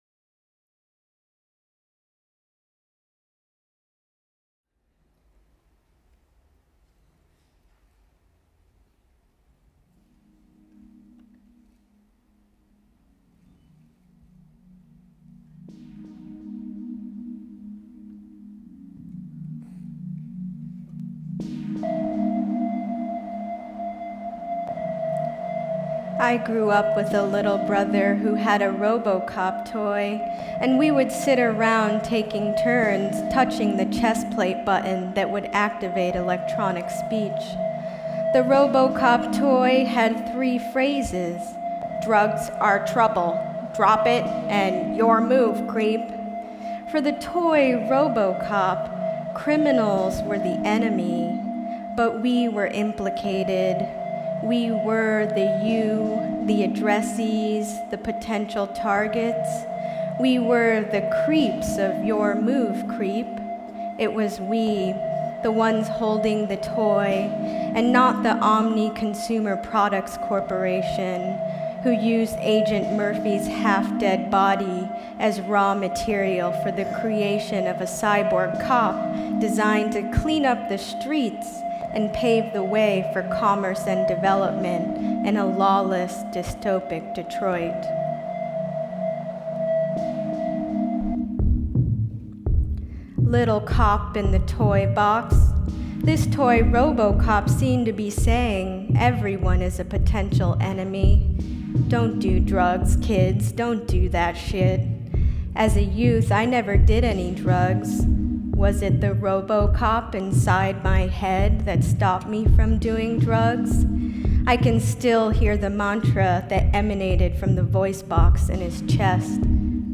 A prison abolitionist punk video-poetry-music mash up about the kind of fucked-up dystopian society (i.e. our society) that makes promotional toys for the movie Robocop that actually shout at kids and